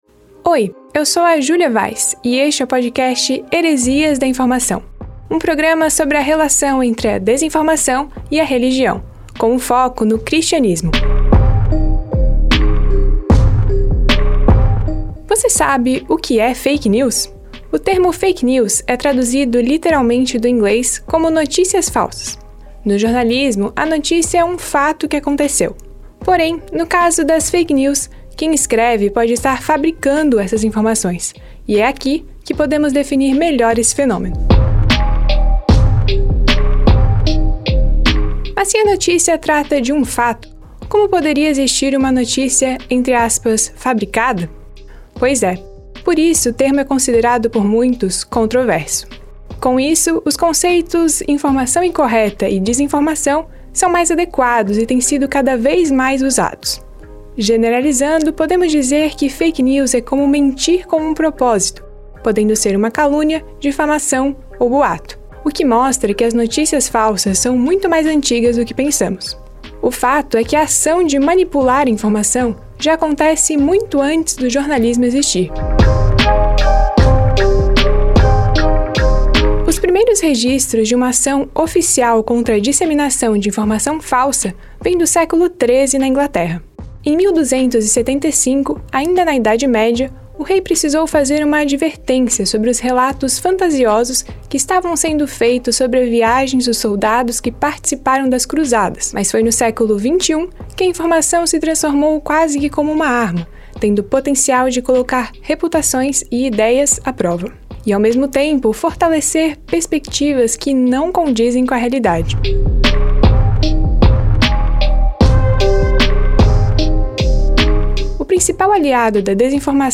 A metodologia usada é a de reportagem, trazendo entrevistas com especialistas e também membros de Igrejas cristãs.
O público alvo são jovens adultos entre 20 e 35 anos, por isso a linguagem é formal, mas de simples compreensão, especialmente para pessoas que não são religiosas.